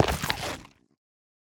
minecraft-assets/assets/minecraft/sounds/block/netherrack/step4.ogg at d45db6d71c388c503bf151e50071a33cdd8d7e90